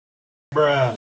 Funny sound effects
bruh.wav